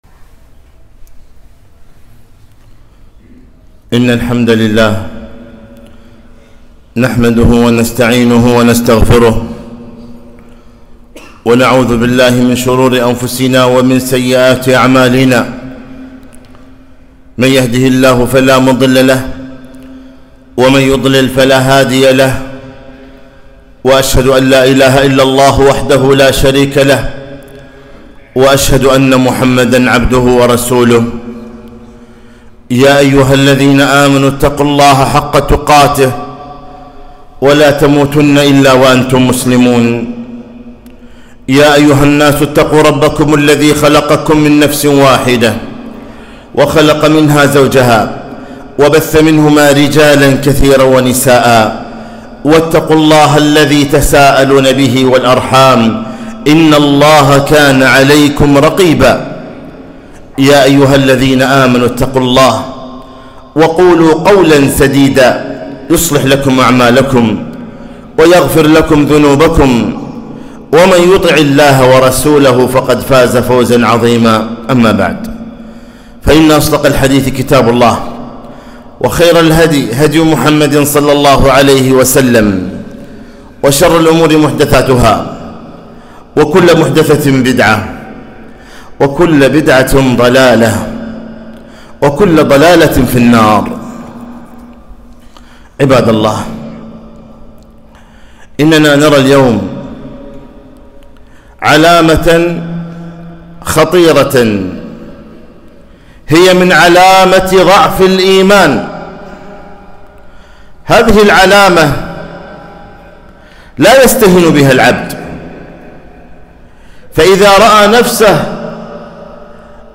خطبة - كيف أنت مع صلاة الفجر؟